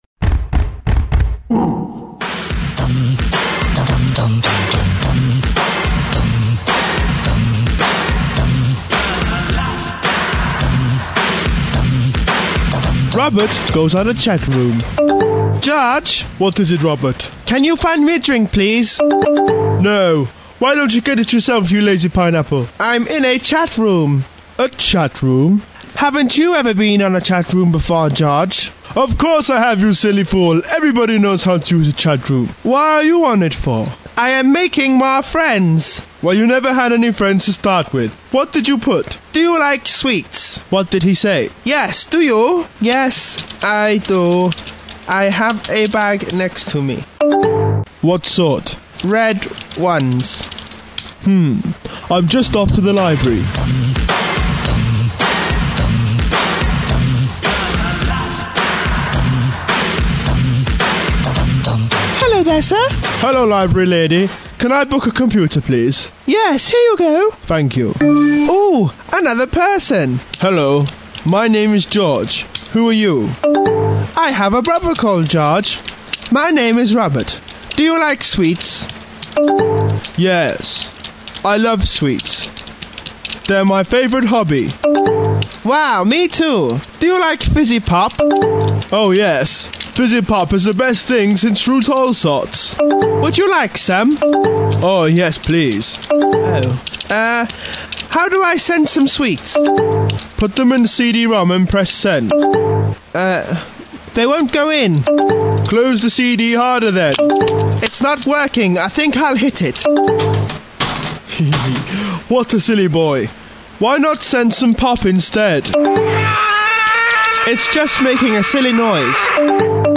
Aired on a little known internet radio station in 2005, Robert and George follows two brothers and their calamities in the modern world.